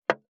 552肉切りナイフ,まな板の上,
効果音